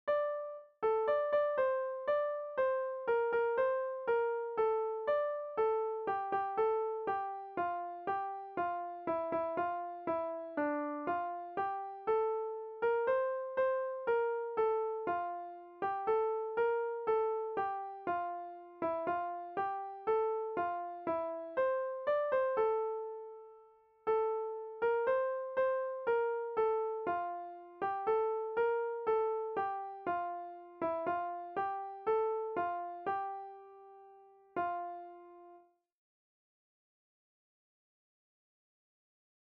Nahrávky živých muzikantů si můžete poslechnout u písniček Vločka a Jinovatka, u ostatních si můžete poslechnout zatím jen melodie generované počítačem 🙂